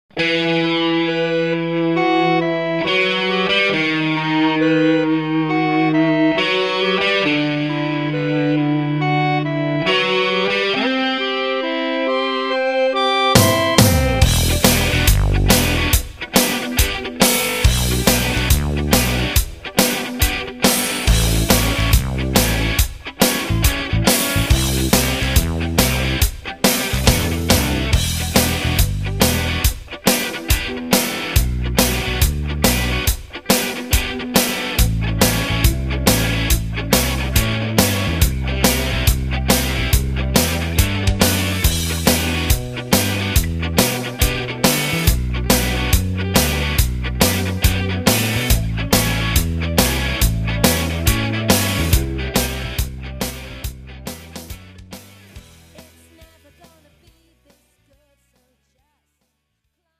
(팝송) MR 반주입니다.